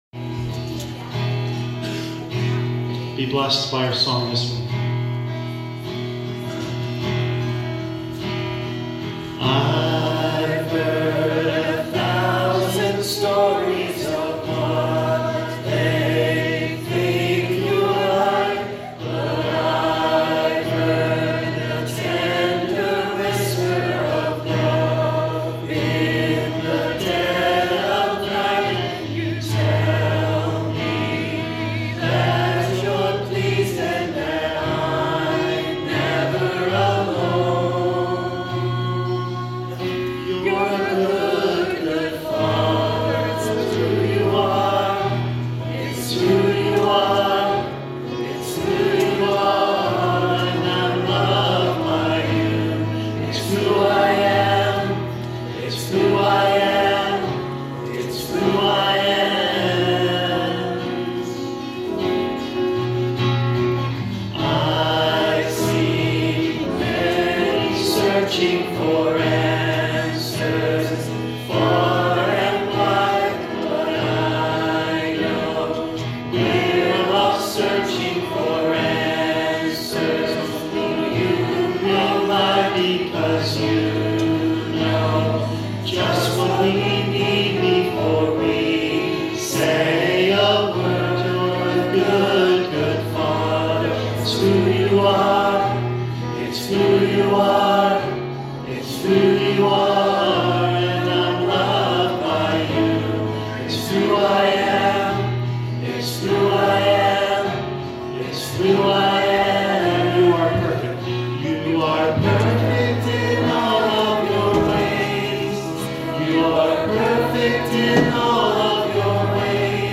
Canticle of Praise "Good, Good Father"